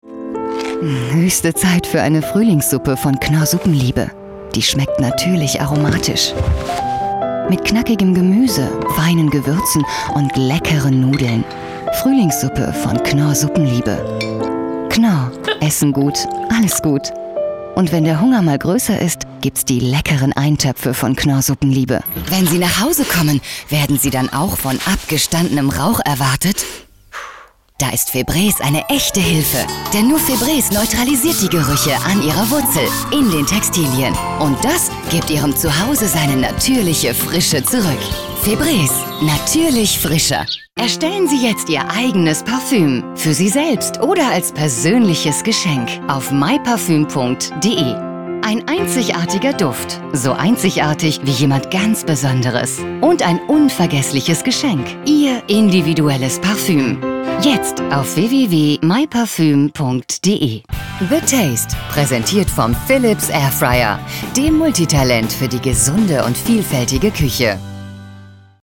seit über 20 Jahren Profi-Sprecherin, bekannte Stimme aus Funk und TV, Werbung und Service-Telefonie, volle, warme, weibliche Stimme, sehr wandelbar von werblich über seriös/ernst zu informativ und freundlich
Kein Dialekt
Sprechprobe: Werbung (Muttersprache):